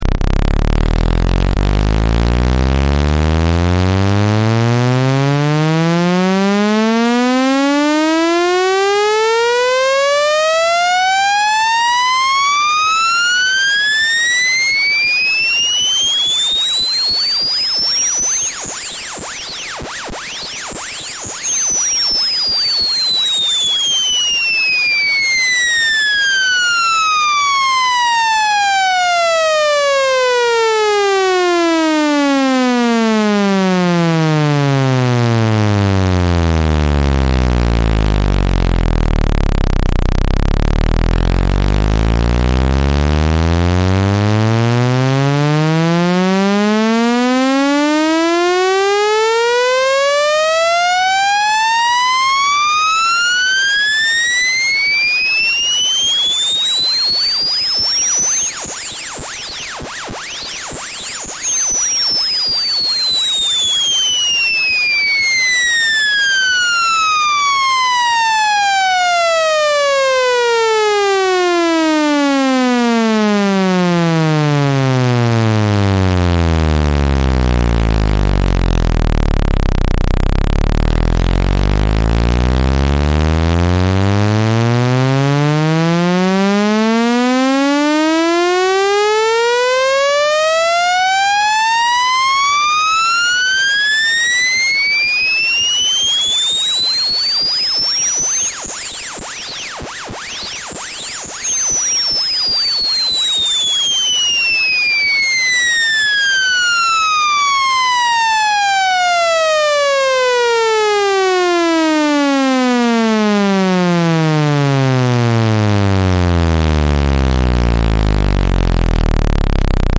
Speaker Cleaner Saund Sound Effects Free Download